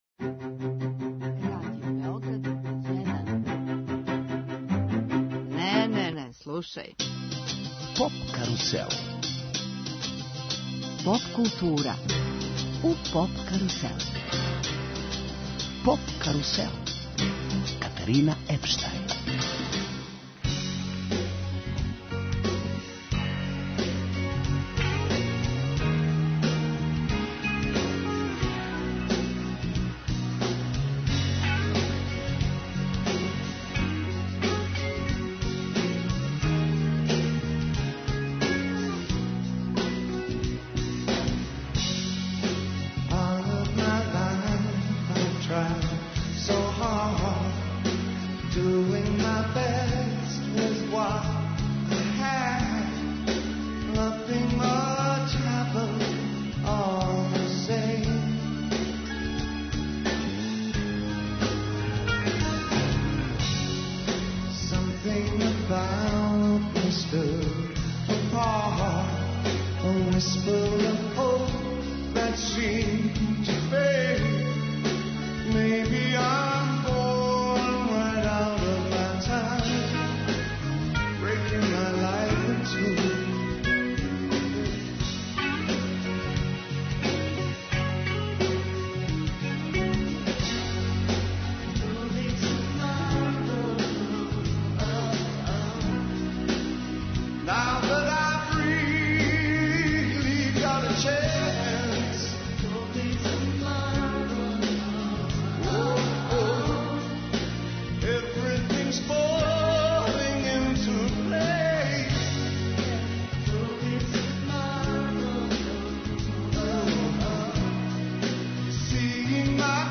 емитујемо мини концерт